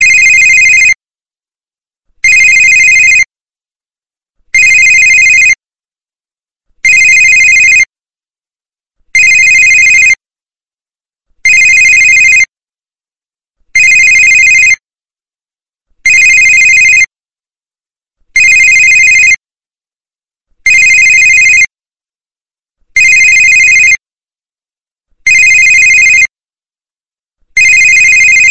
Kategorien: Telefon